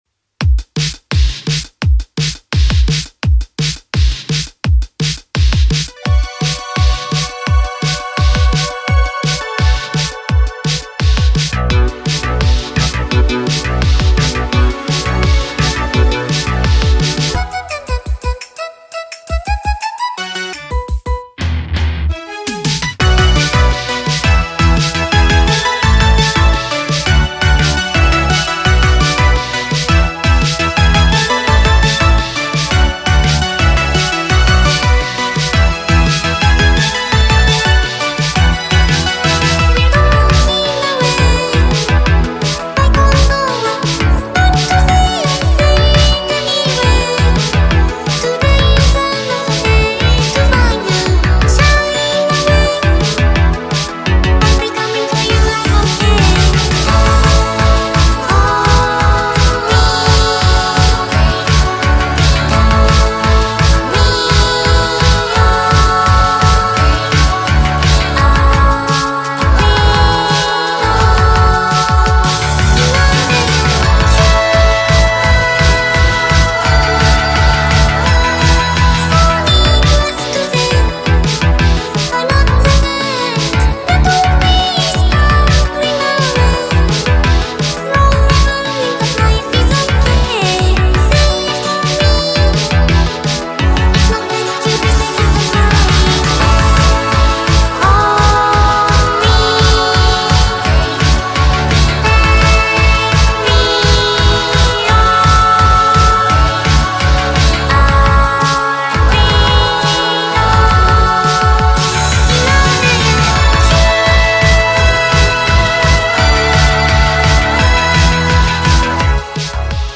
BPM170-170
Audio QualityMusic Cut